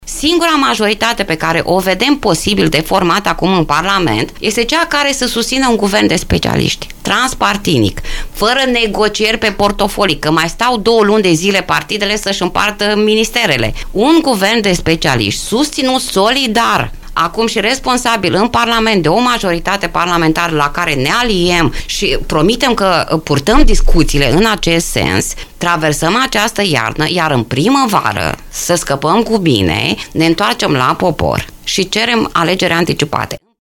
Ea a declarat postului nostru de radio că scrutinul ar trebui să se desfășoare în primăvara anului viitor și că, până atunci, Guvernul să fie format din tehnocrați fără culoare politică.